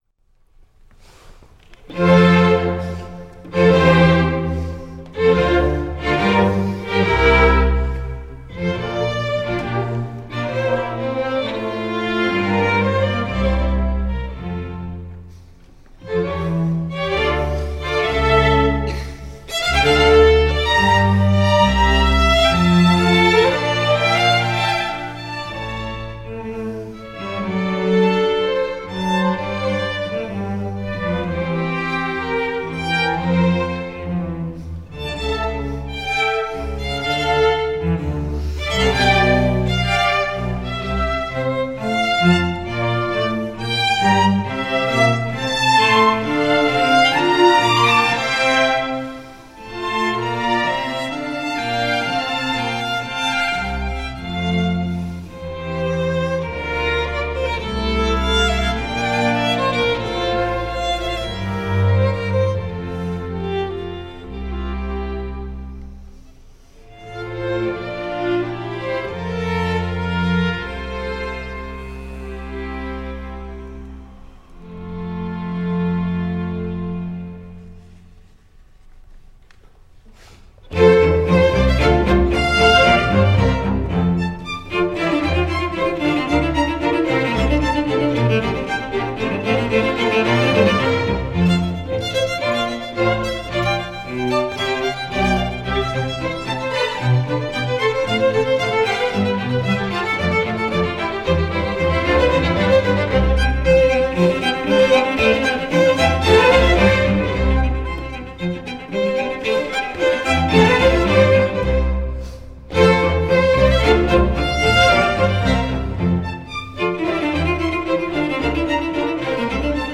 The Boston-based unconducted chamber orchestra A Far Cry perform live in the Koret Auditorium of the de Young Museum in San Francisco on May 15, 2008 during their inaugural west coast tour.